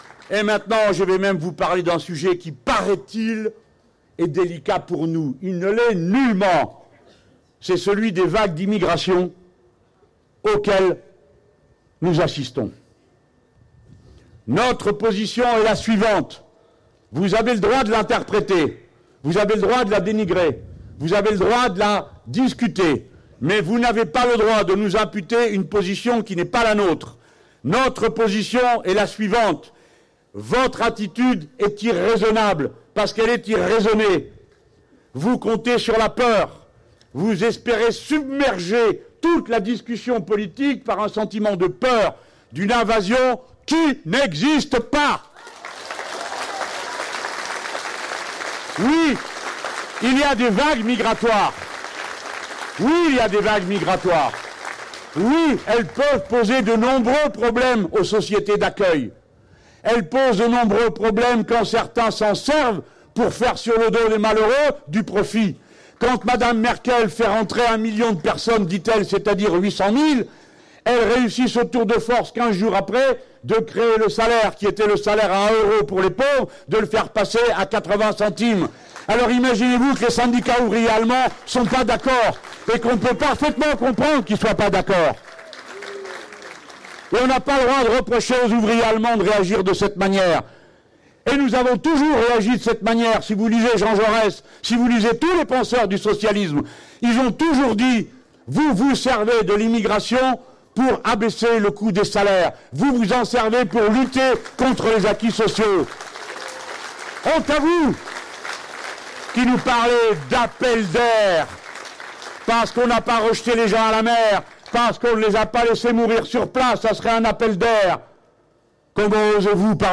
Nous allons inciter les Français à lui mettre une raclée démocratique», lance Jean-Luc Mélenchon devant quelque 2 000 personnes réunies à Marseille dans le cadre de l’université d’été de la France Insoumise.